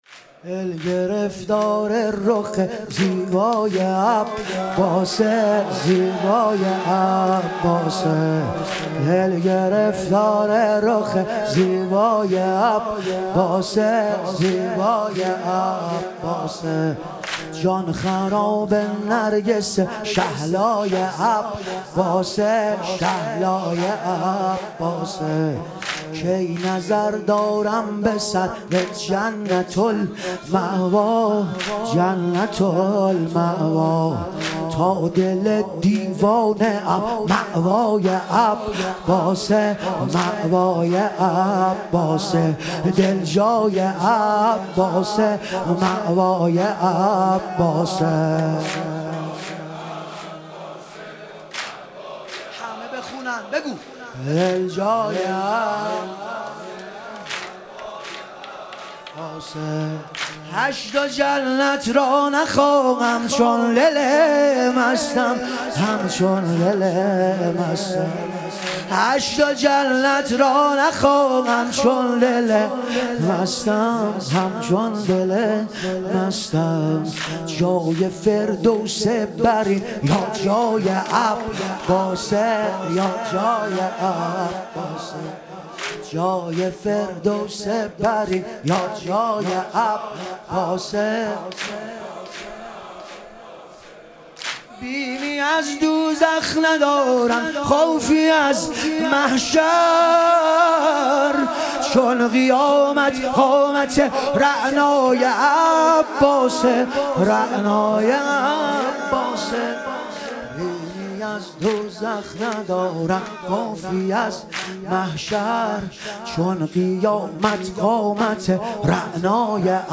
شهادت امام باقر علیه السلام - واحد - 10 - 1403